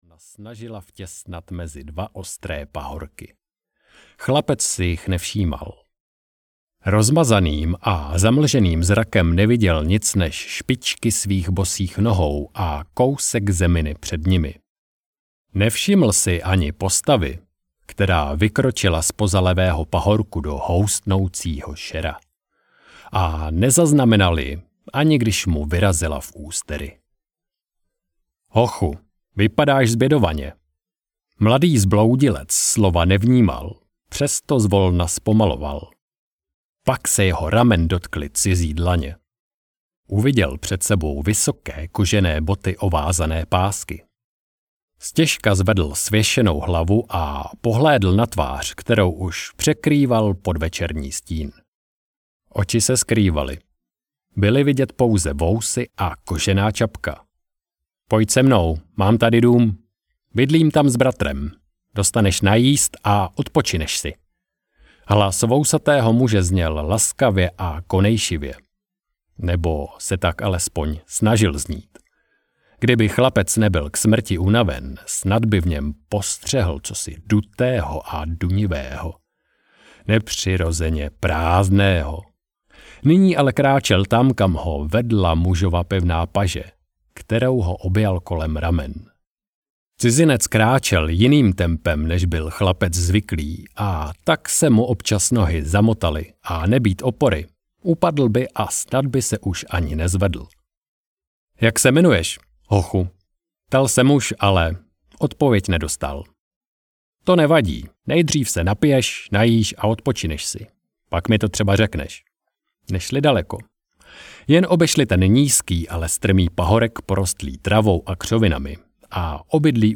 Běsi z temného hvozdu audiokniha
Ukázka z knihy
besi-z-temneho-hvozdu-audiokniha